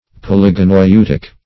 polygoneutic - definition of polygoneutic - synonyms, pronunciation, spelling from Free Dictionary
Search Result for " polygoneutic" : The Collaborative International Dictionary of English v.0.48: Polygoneutic \Pol`y*go*neu"tic\, a. [Poly- + Gr.